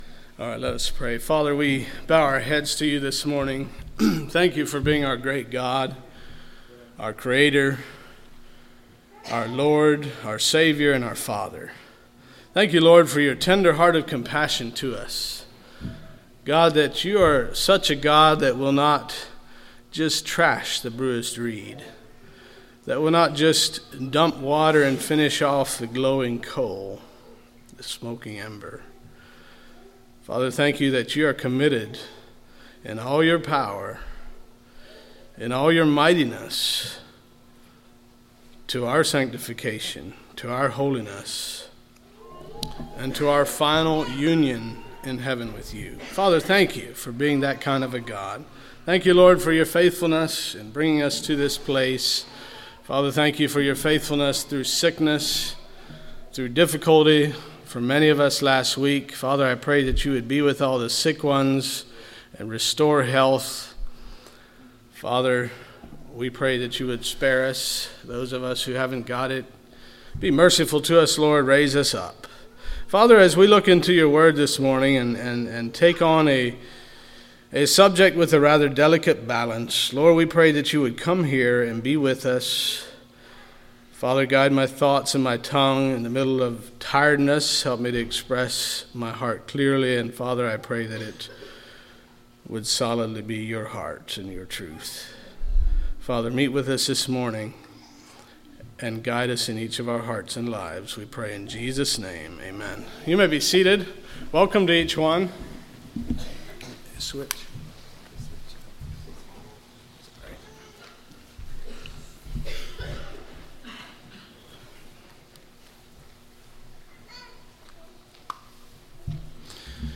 Sermons of 2024 - Blessed Hope Christian Fellowship